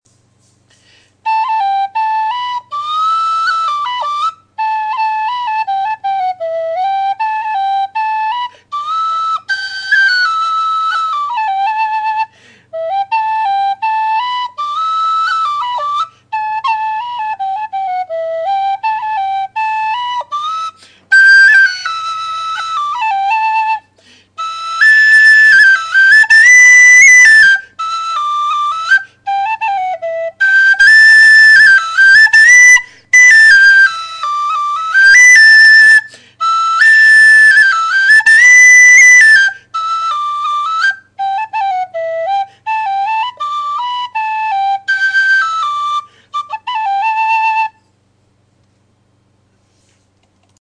Alba Soprano D
Strong tone, with a loud and brash second octave. Has an underlying hiss that increases as you go up the range.
Sound clips of the whistle: